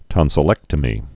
(tŏnsə-lĕktə-mē)